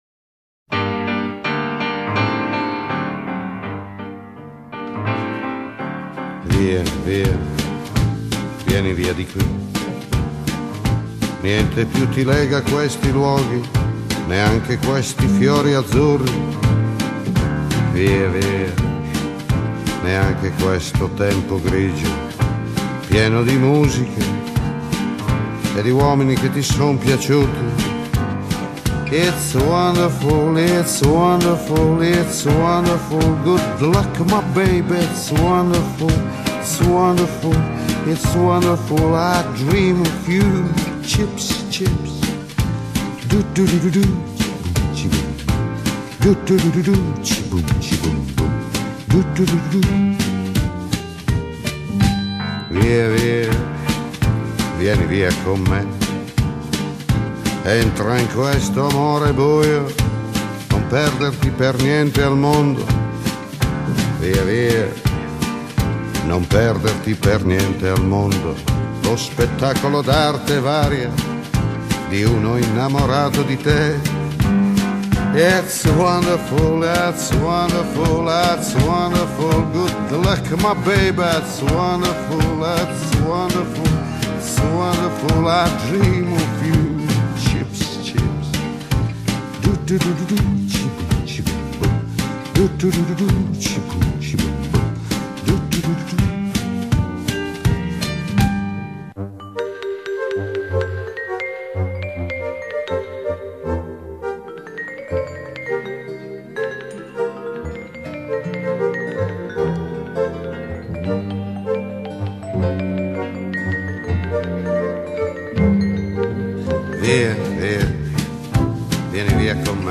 Gli Eufonika suonano nel Swing Corner del Palafiori
Tutti noiosi brani statunitensi da sottofondo per cene al ristorante